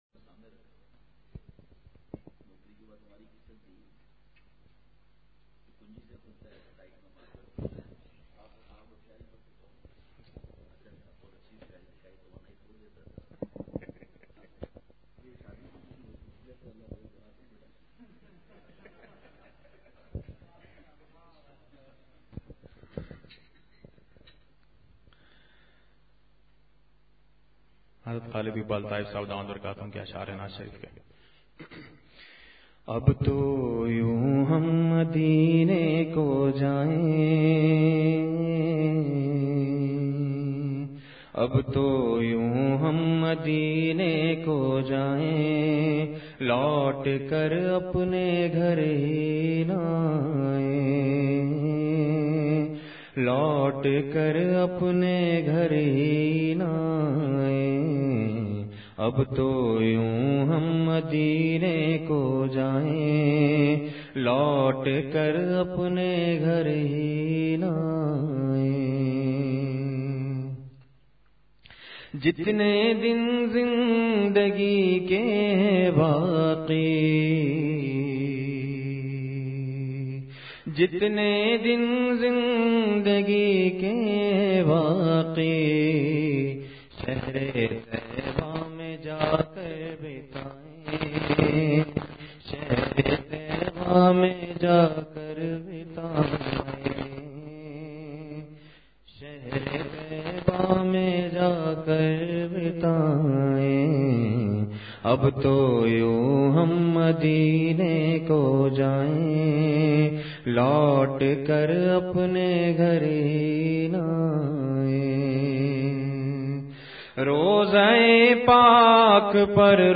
خاص بیان ۱۶فروری ۲۰۱۹ء: دین داری سو فیصد شریعت و سنت پر عمل کرنے کا نام ہے